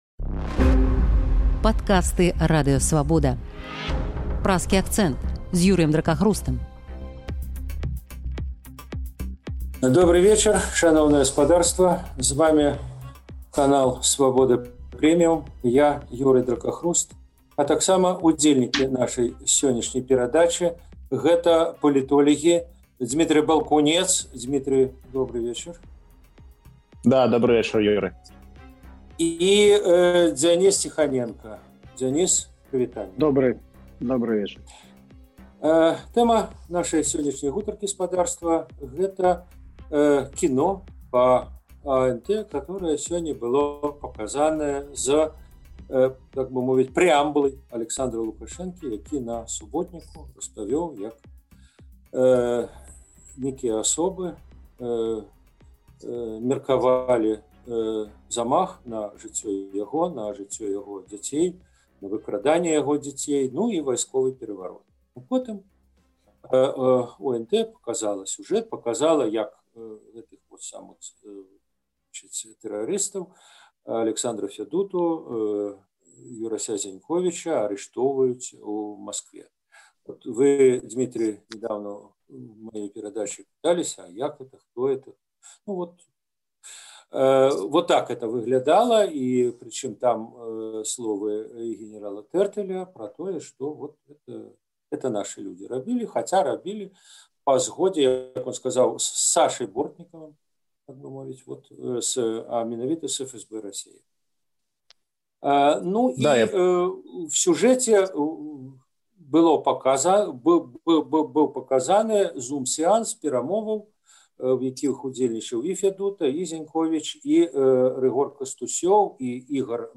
Гэтыя пытаньні ў Праскім акцэнце абмяркоўваюць палітолягі